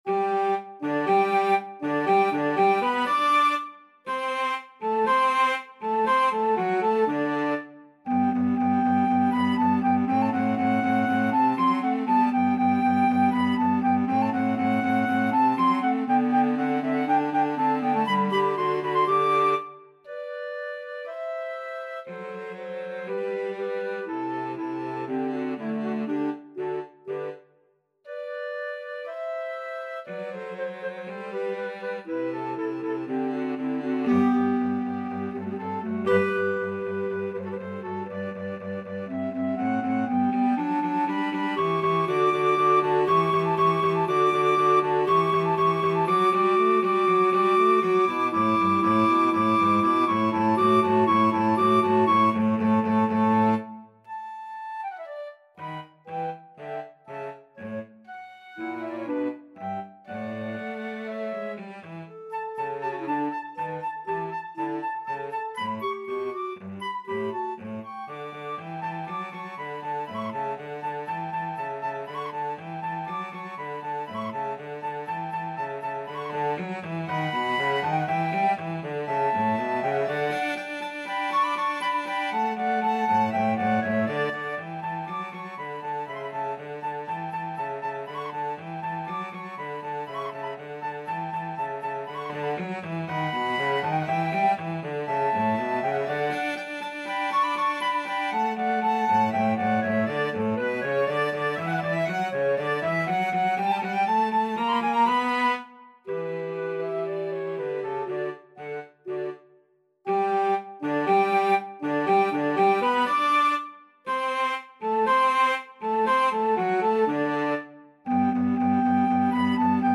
Flute
Clarinet
Cello
Allegro (View more music marked Allegro)